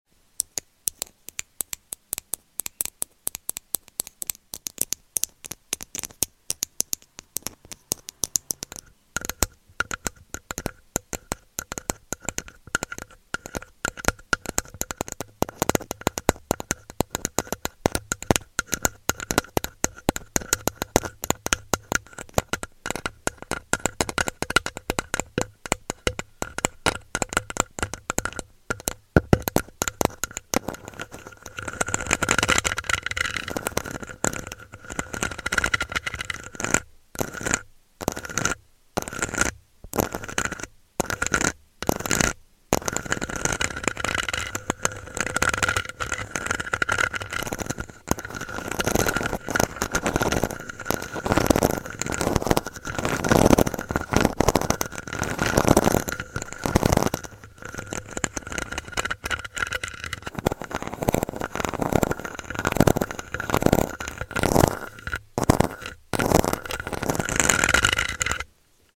Tapping and Scratching the Mic sound effects free download